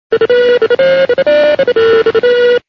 here is the official "it's the Queen calling" ringtone used by ministers and other high officials of Her Majesty's Government.